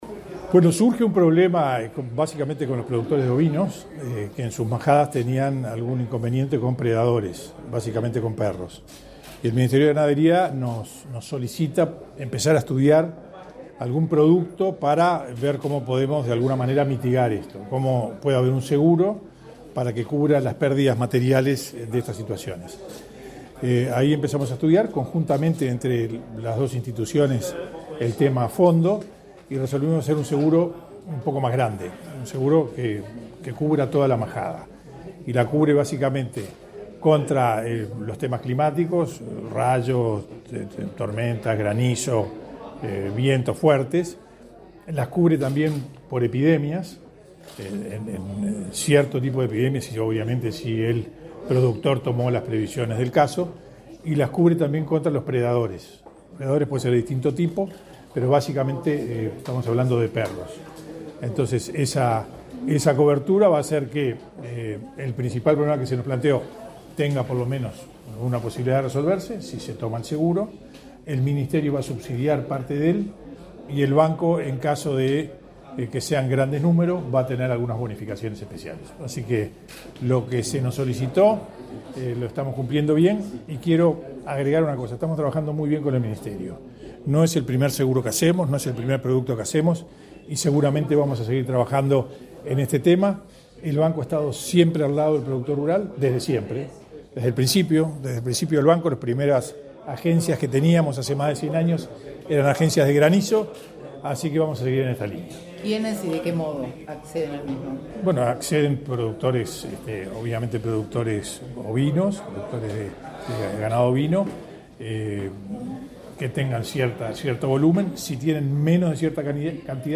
Declaraciones a la prensa del presidente del BSE
Declaraciones a la prensa del presidente del BSE 28/07/2022 Compartir Facebook X Copiar enlace WhatsApp LinkedIn El presidente del Banco de Seguros del Estado (BSE), José Amorín Batlle, y el ministro de Ganadería, Fernando Mattos, firmaron un convenio para instrumentar un seguro para productores ovinos. Luego, Amorín Batlle dialogó con la prensa.